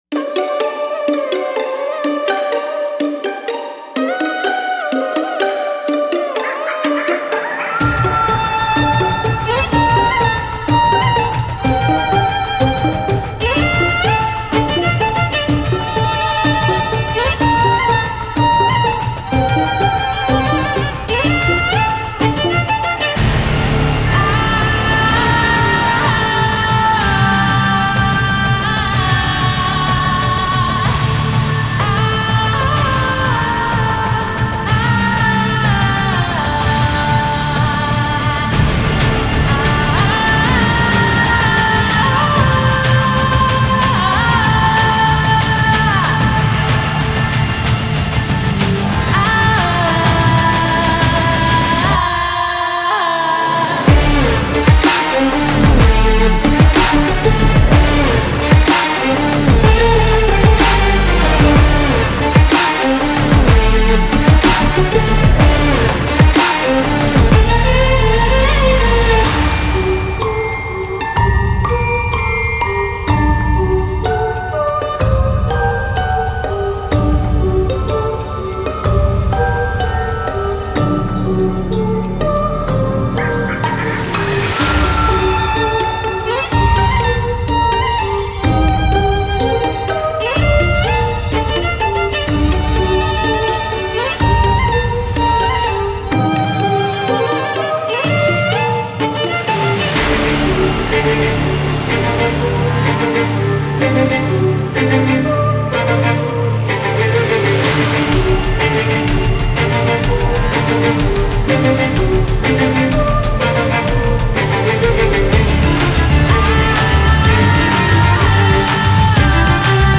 • Жанр: Electronic, Instrumental